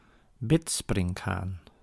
Ääntäminen
Ääntäminen Tuntematon aksentti: IPA: /ˈbɪtsprɪŋk(ɦ)aːn/ Haettu sana löytyi näillä lähdekielillä: hollanti Käännös 1. αλογάκι της Παναγίας {n} (alogáki tis Panagías / alogáki tis Panayías) Suku: m .